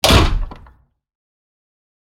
doorslam.wav